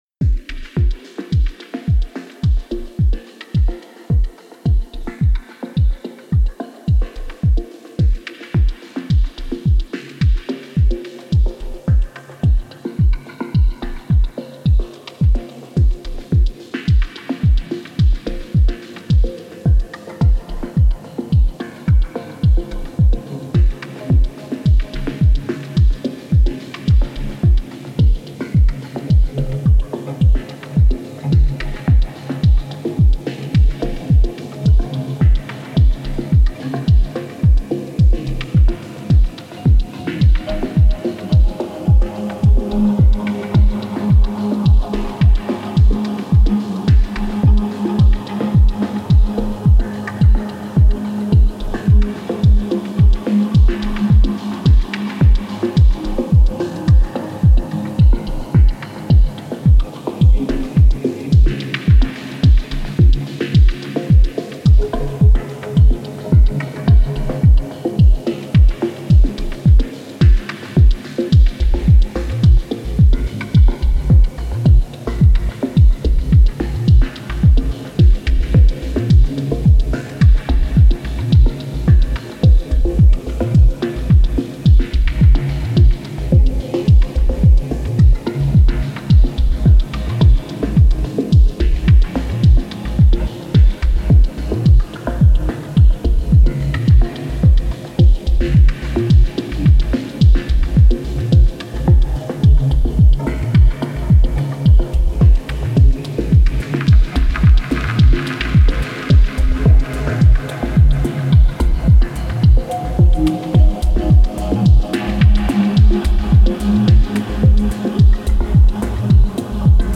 Genre: Dub Techno/Ambient/Deep House.